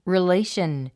発音 riléiʃən リレイション